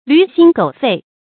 驢心狗肺 注音： ㄌㄩˊ ㄒㄧㄣ ㄍㄡˇ ㄈㄟˋ 讀音讀法： 意思解釋： 比喻人心兇狠惡毒。